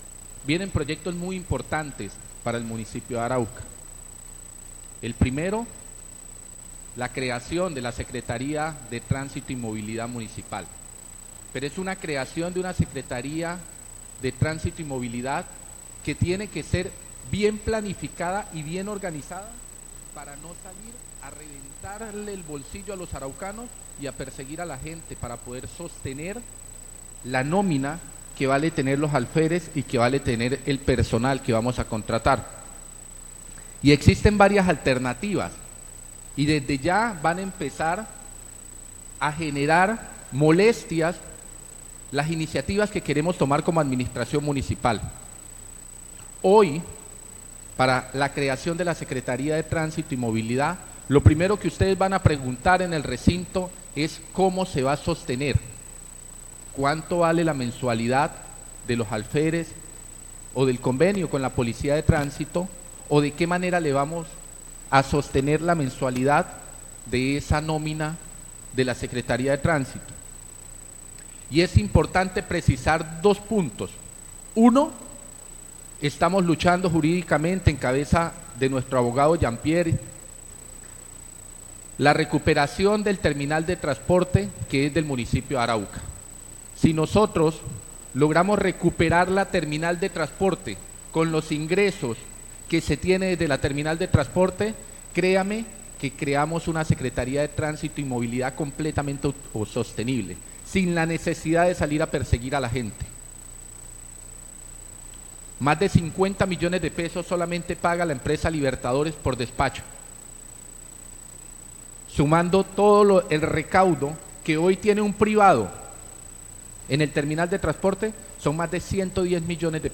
Durante la instalación del primer periodo de sesiones ordinarias del Concejo Municipal, el alcalde de Arauca, Juan Qüenza, explicó en detalle las alternativas financieras que permitirían sostener la Secretaría de Tránsito y Movilidad Municipal sin generar cargas adicionales para la ciudadanía.